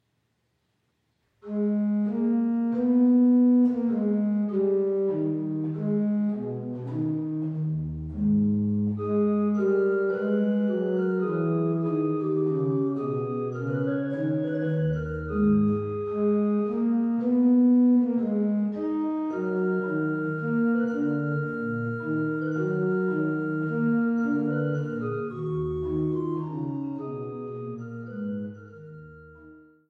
an der historischen Orgel zu Niederndodeleben
Orgel